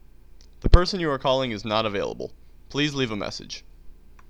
default_outgoing_message.wav